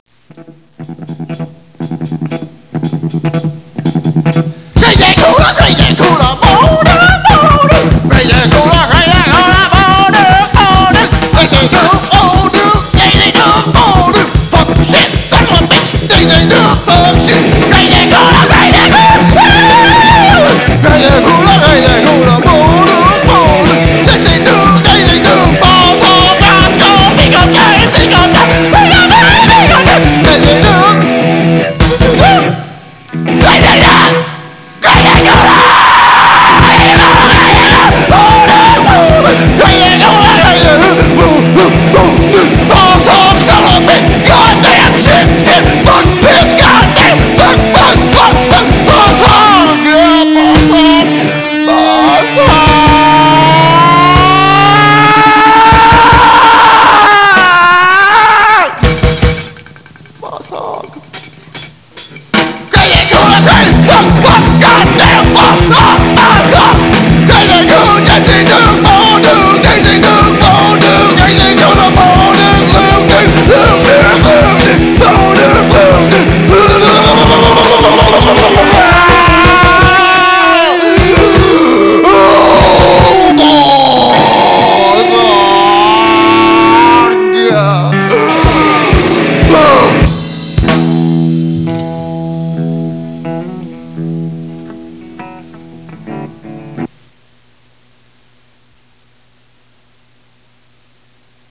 This is a song we recorded during the 2nd 'Better lush than Clever' session...An improvisation
vocals